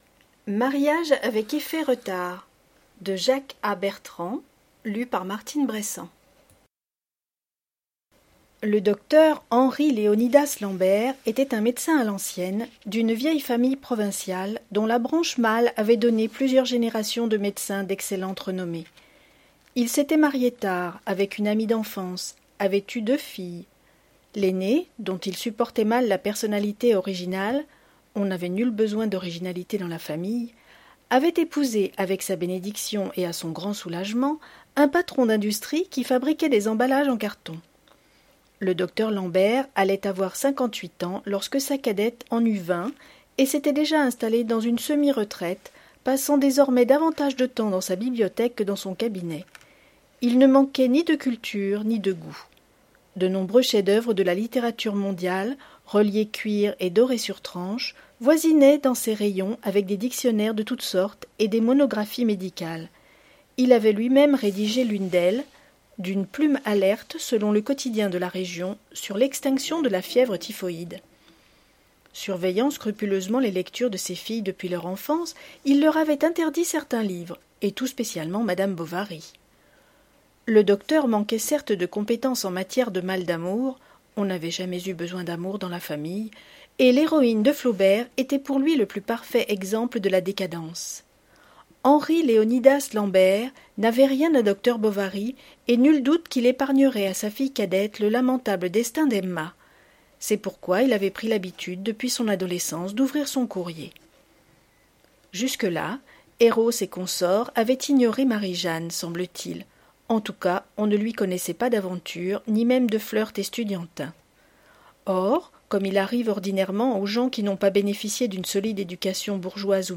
Lecture à haute voix - Mariage avec effet retard